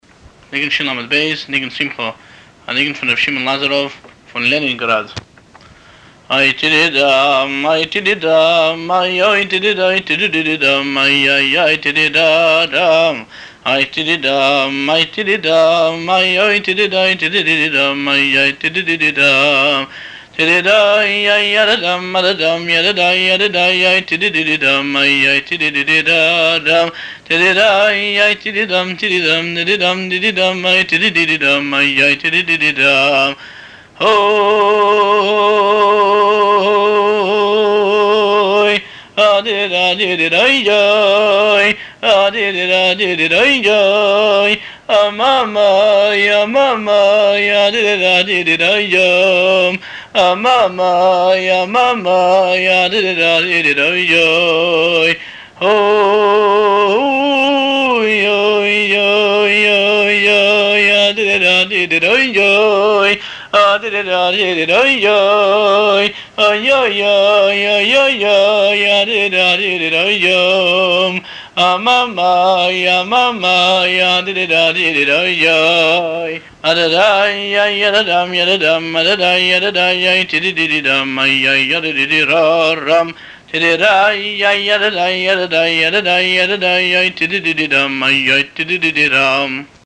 הניגון